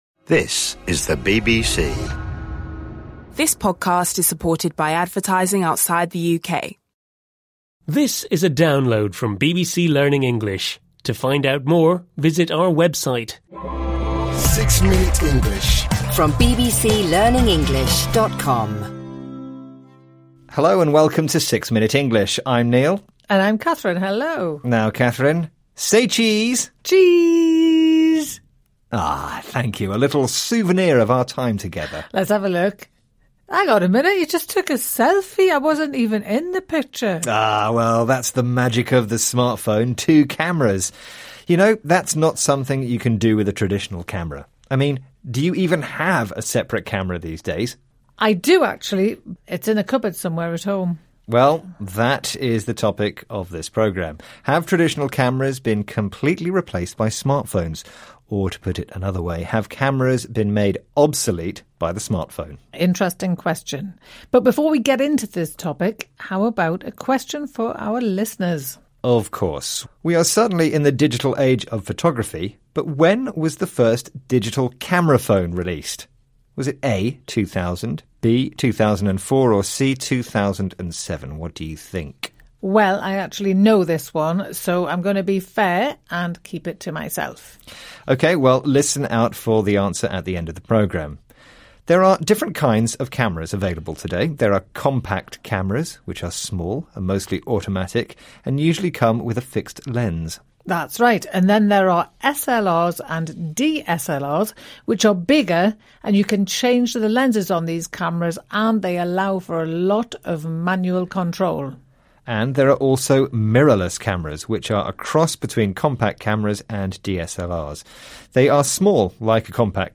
This time, we are looking at a BBC listening module that talks about smart phones.